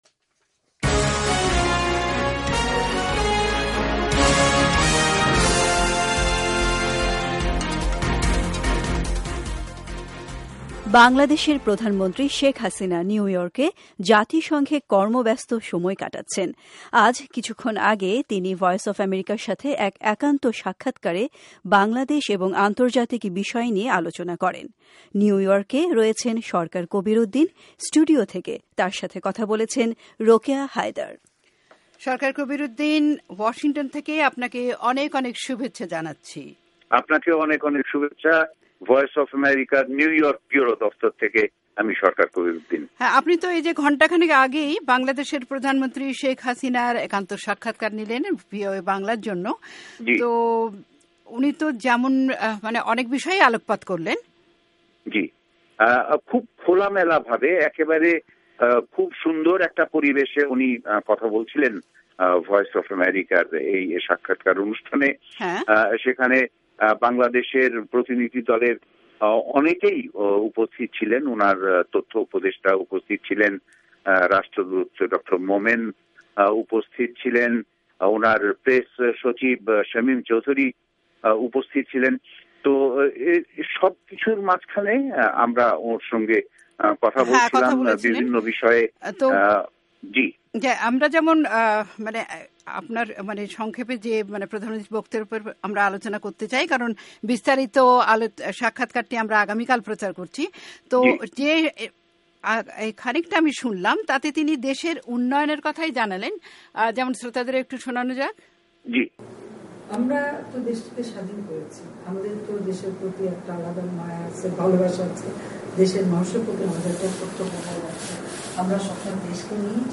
স্টুডিও থেকে তার সাথে কথা বলেছেন